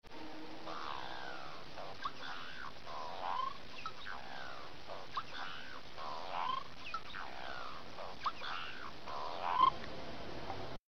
Singing ability:Poor
Song
The cock sings a low-pitched, bubbling warble.
Cock singing (.mp3, .17 MB)